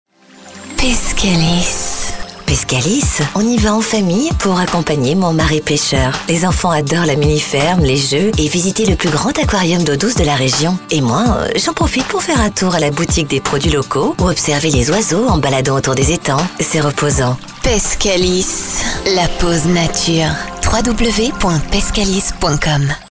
20 years of experience of voice over, on radio Tv and other types of recordings
Sprechprobe: Werbung (Muttersprache):